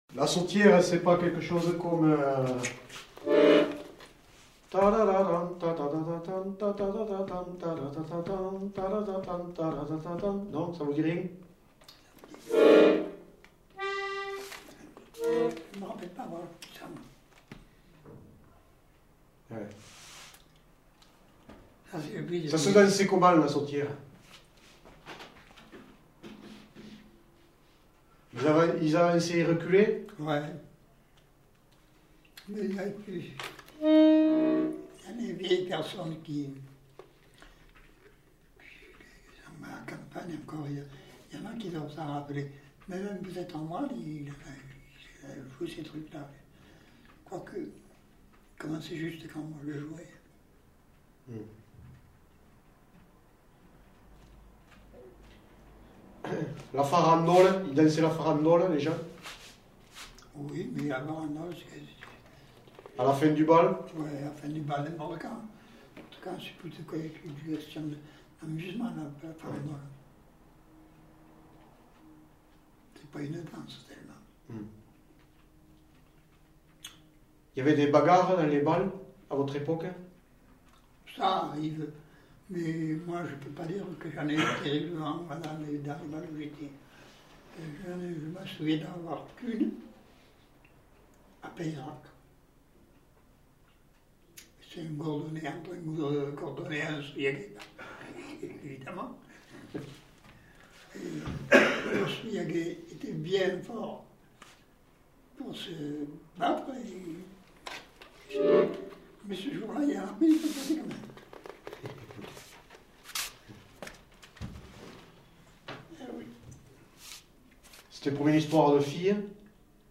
Lieu : Souillac
Genre : témoignage thématique